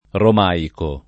rom#iko] o romeico [rom$iko] etn. stor.; pl. m. -ci — voce lett. per «greco moderno», dicendosi ῥωμαίικος (dem. ρωμαίικος) / roméikos [gr. mod. rom$ikoS] (per il classico ῾Ρωμαϊκός / Rhomaïkós [gr. ant. romaik0S]) l’idioma stesso dei greci, ch’era stato un tempo l’idioma dell’Impero romano (d’Oriente) — dalle due forme del greco le due forme dell’it.; cfr. borromaico